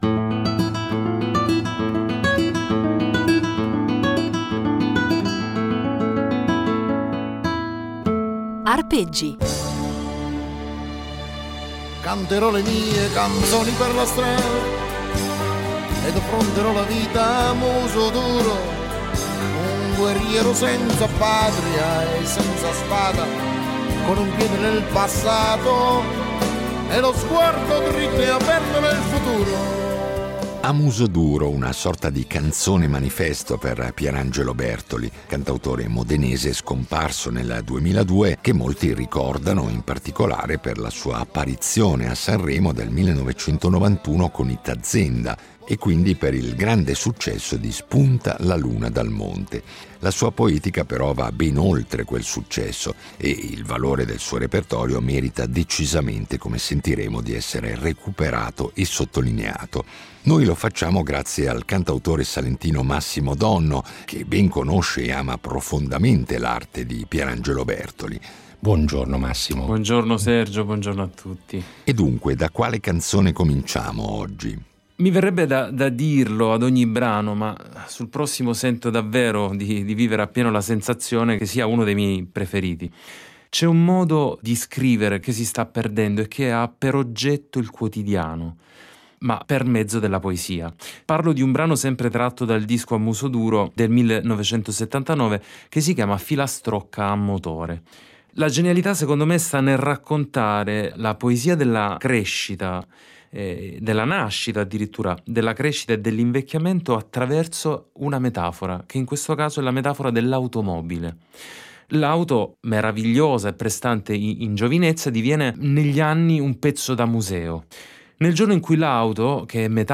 cantautore salentino
chitarra
violoncellista